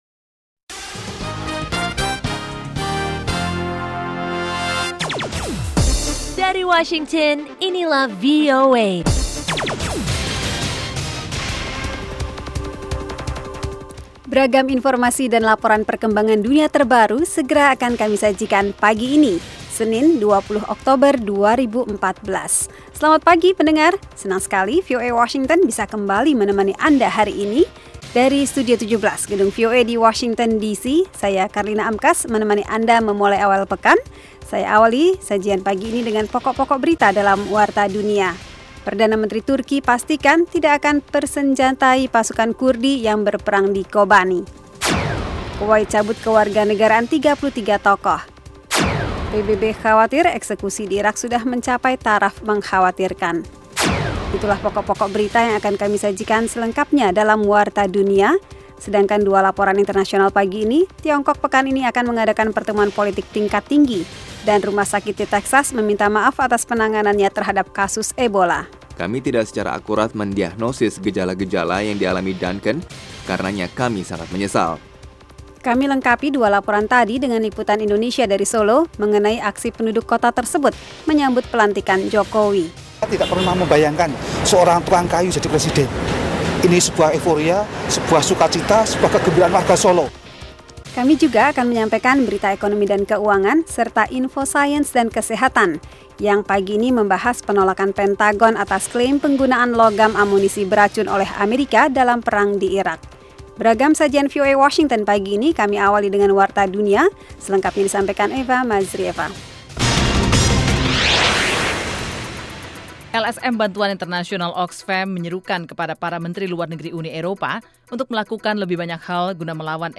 Setiap paginya, VOA menyiarkan sebuah program informatif yang menghidangkan beragam topik yang menarik, berita internasional dan nasional, tajuk rencana, bisnis dan keuangan, olah raga, sains dan kesehatan, musik dan tips-tips pengembangan pribadi.